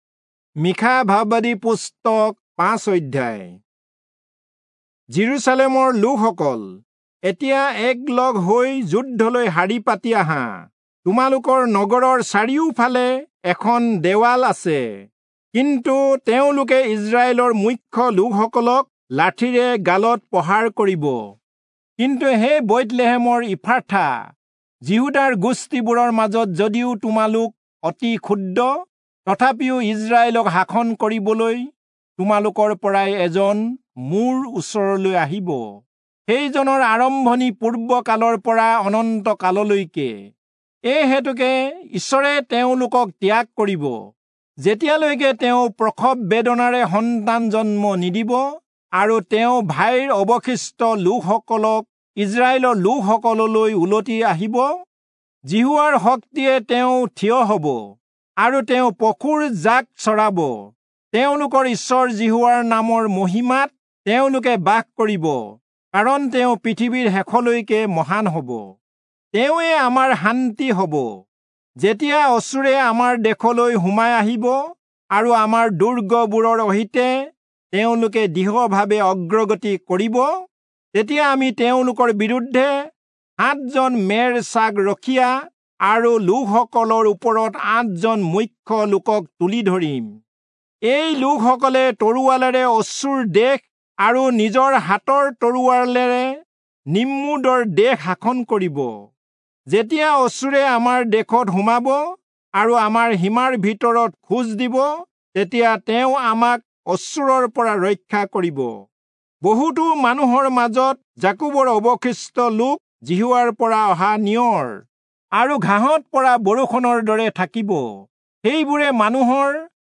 Assamese Audio Bible - Micah 5 in Irvor bible version